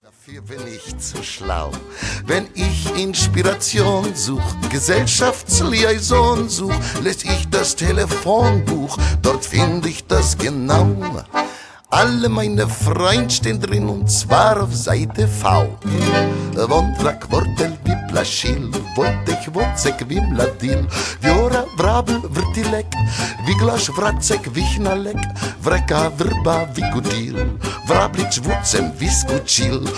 World Music From Berlin
Bohemian Polka